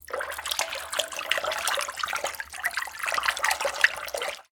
water08
bath bubble burp click drain dribble drip dripping sound effect free sound royalty free Nature